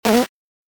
doubleJump.ogg